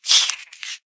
MinecraftConsoles / Minecraft.Client / Windows64Media / Sound / Minecraft / mob / silverfish / kill.ogg